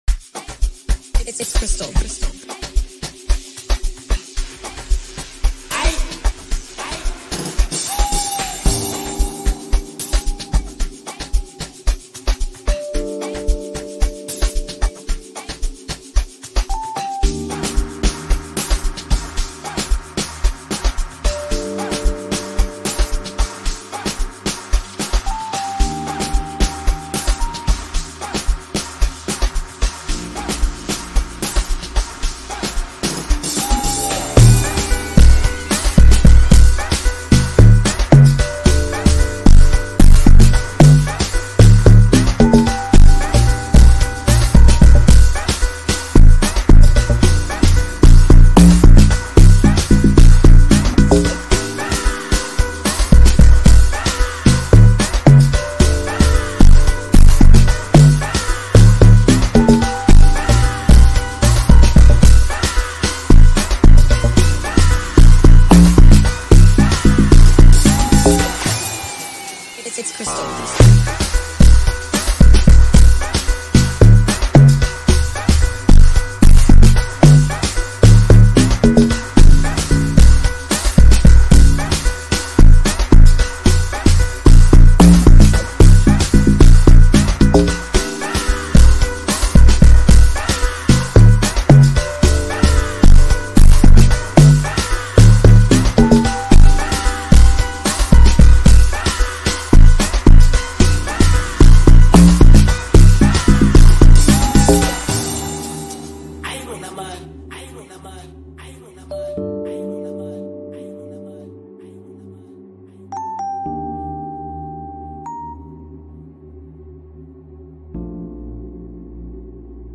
Home » Amapiano
South African singer-songwriter